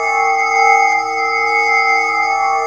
A#4 NEURO05R.wav